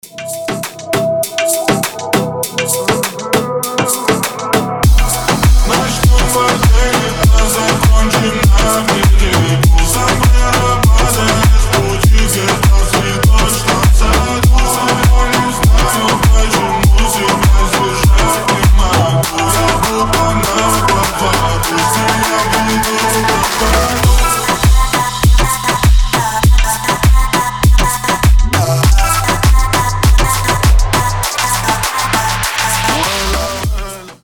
• Качество: 320, Stereo
ритмичные
восточные мотивы
Club House